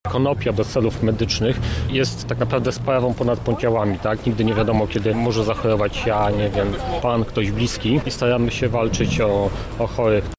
Manifestacje odbyły się w sobotę pod lubelskim Ratuszem.